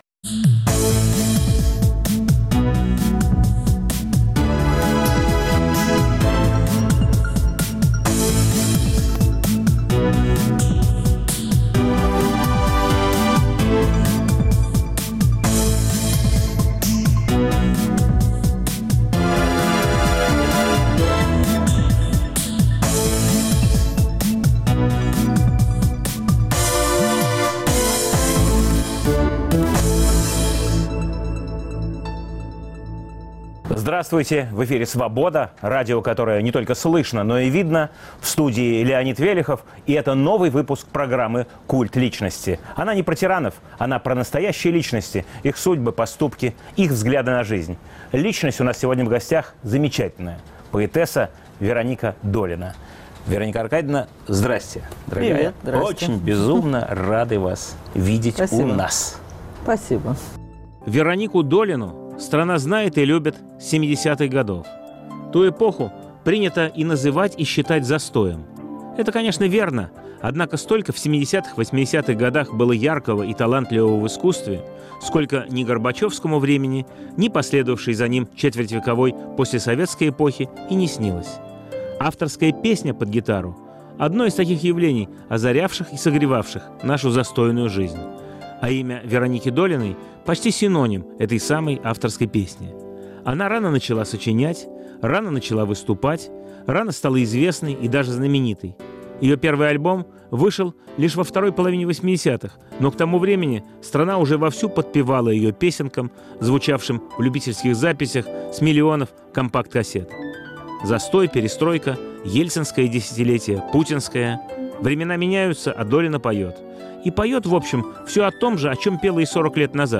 Новый выпуск программы о настоящих личностях, их судьбах, поступках и взглядах на жизнь. В студии поэтесса Вероника Долина.